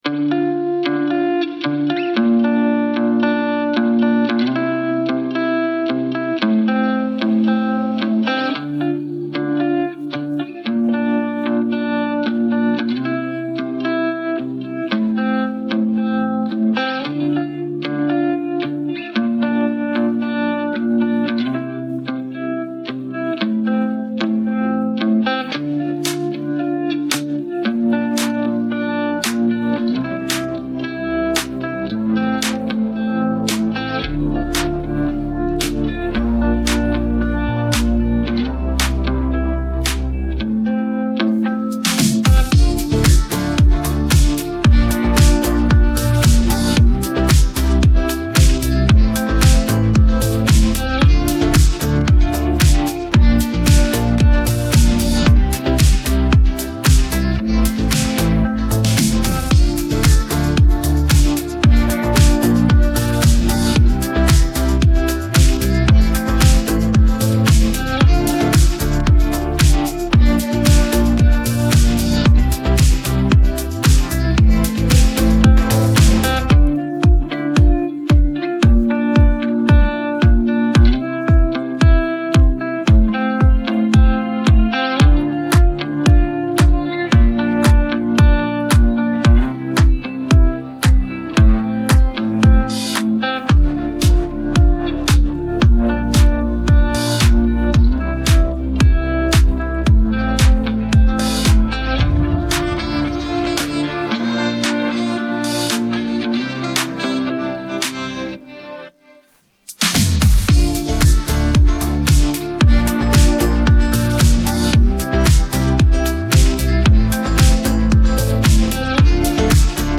Música de fundo